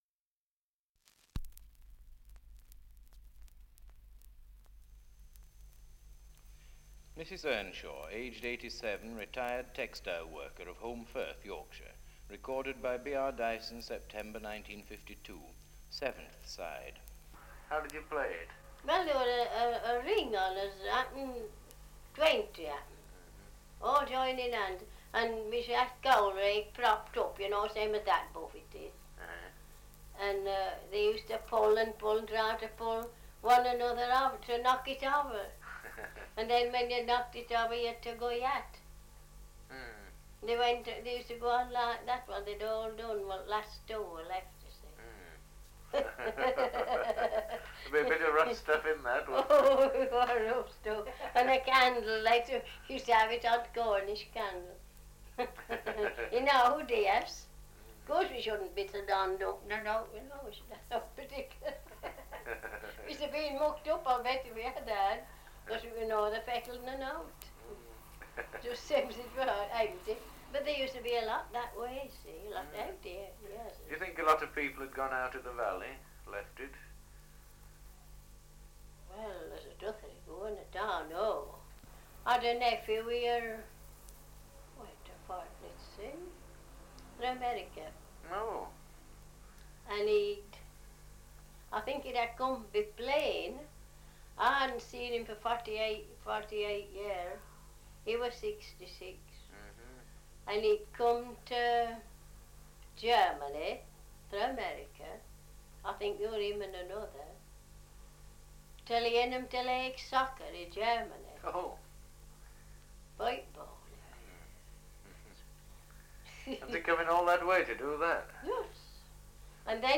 Title: Survey of English Dialects recording in Holmbridge, Yorkshire
78 r.p.m., cellulose nitrate on aluminium